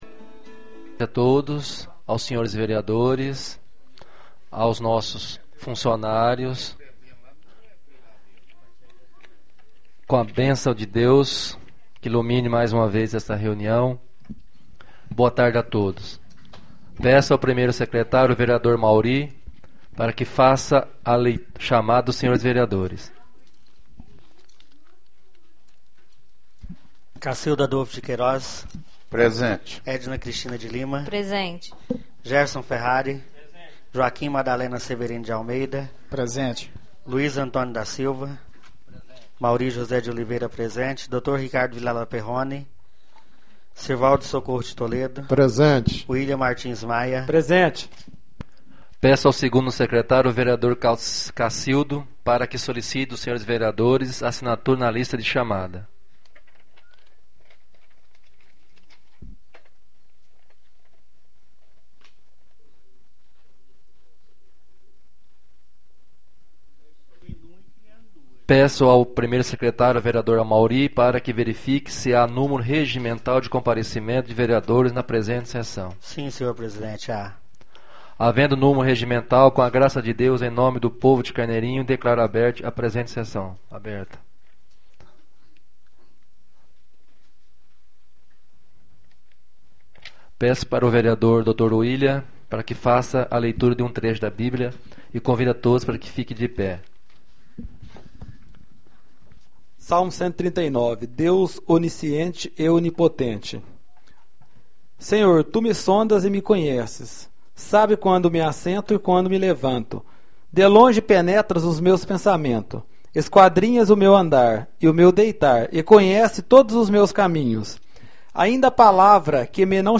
Primeira sessão extraordinária de 2011, realizada no dia 23 de Maio de 2011, na sala de sessões da Câmara Municipal de Carneirinho, Estado de Minas Gerais.